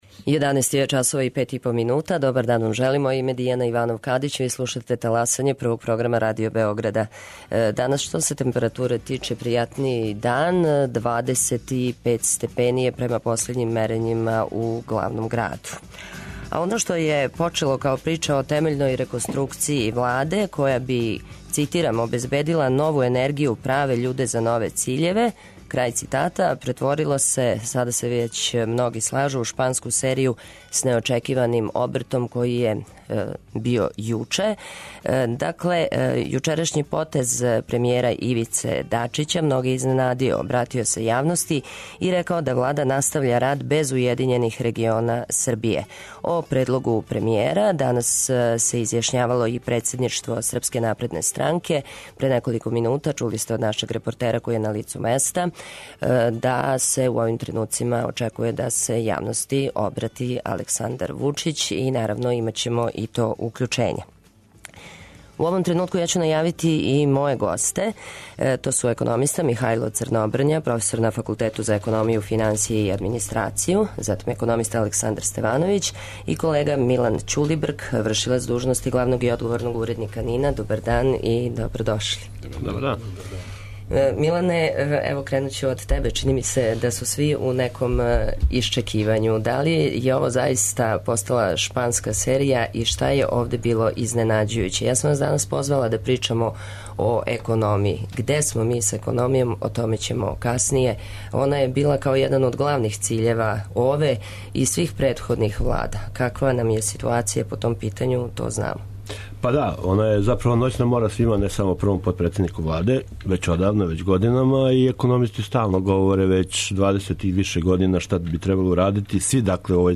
Гости: економисти